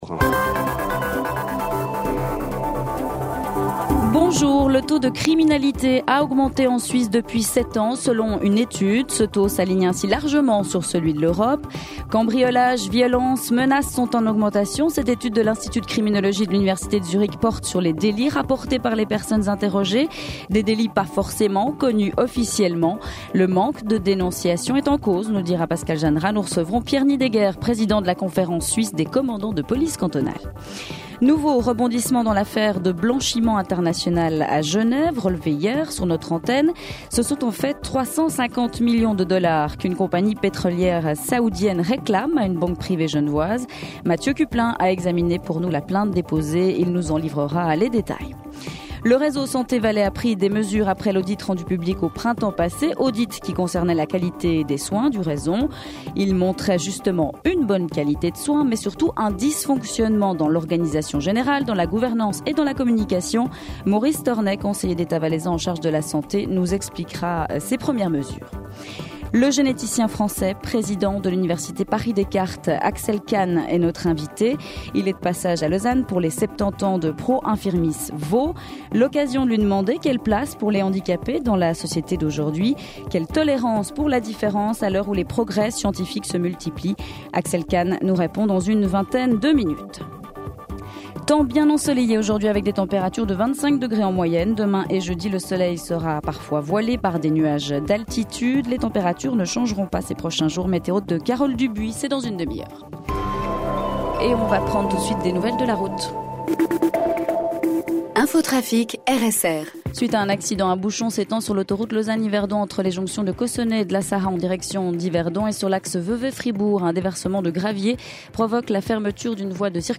Le 12h30, c’est le grand rendez-vous d’information de la mi-journée. L’actualité dominante y est traitée, en privilégiant la forme du reportage/témoignage pour illustrer les sujets forts du moment.